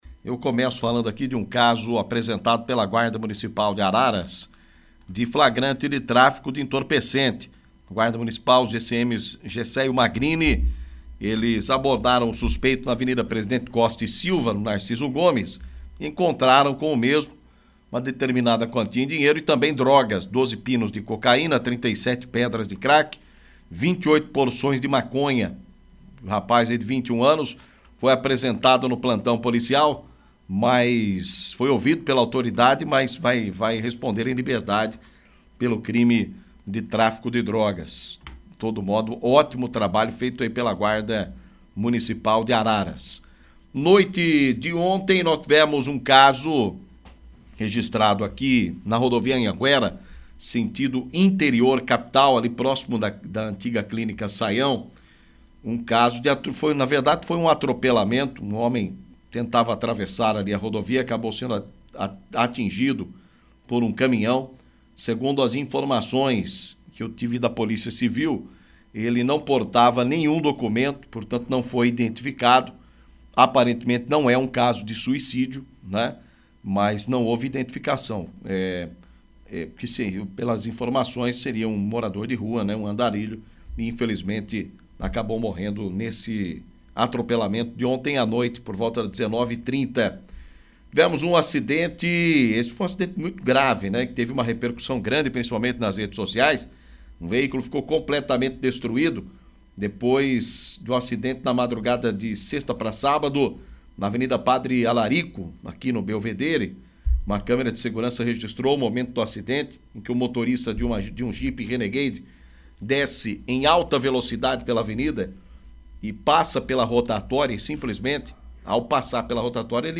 Destaque Polícia